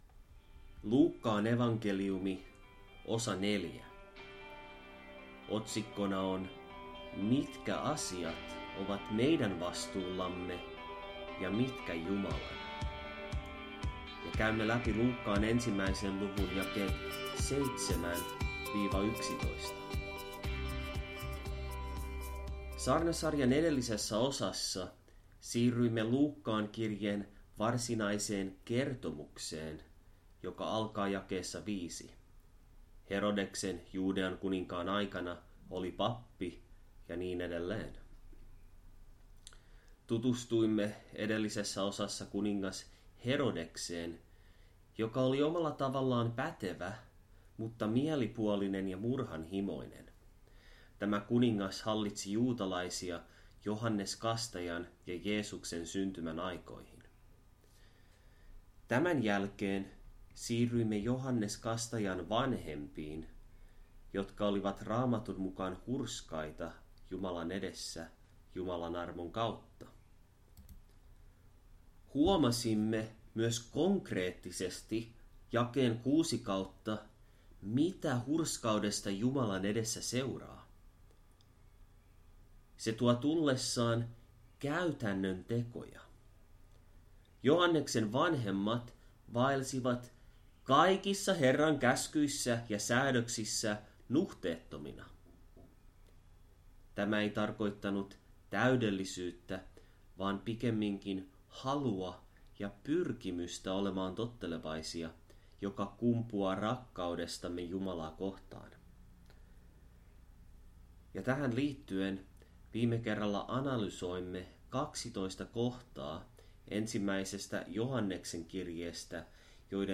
Johdanto